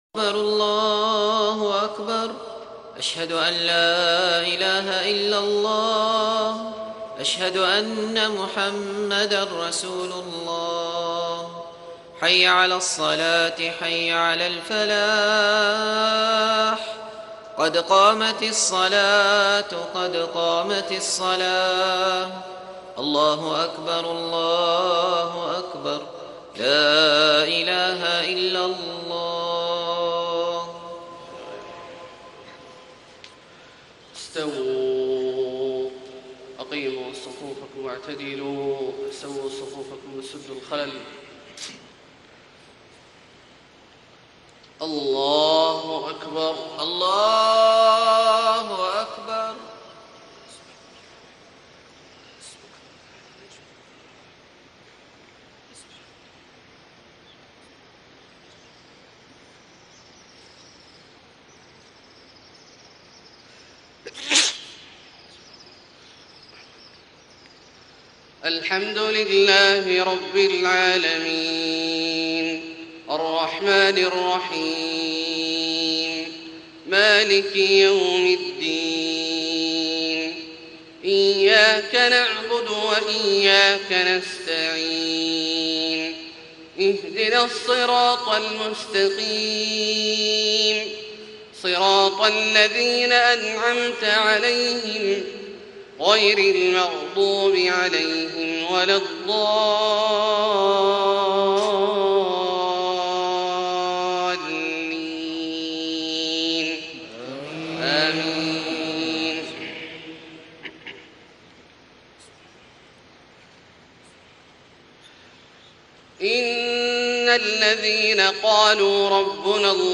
صلاة الفجر 9-8-1434 من سورة الأحقاف > 1434 🕋 > الفروض - تلاوات الحرمين